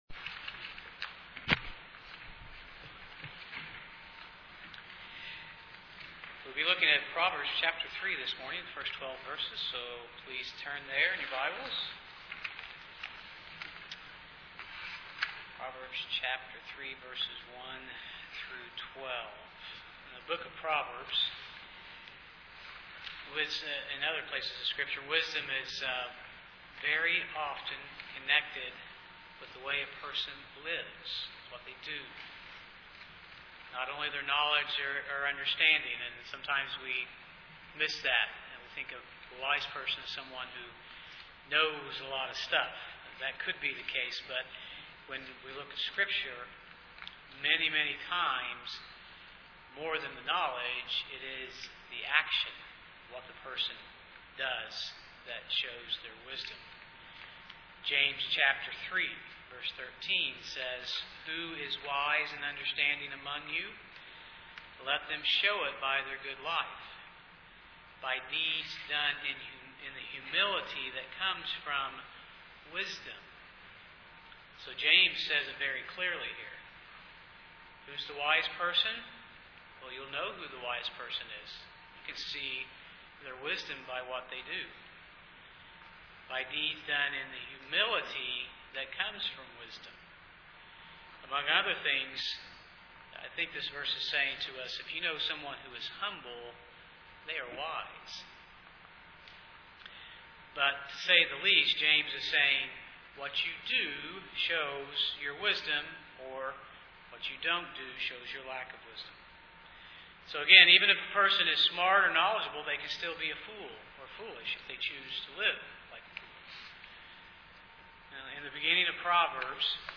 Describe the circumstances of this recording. Passage: Proverbs 3:1-12 Service Type: Sunday morning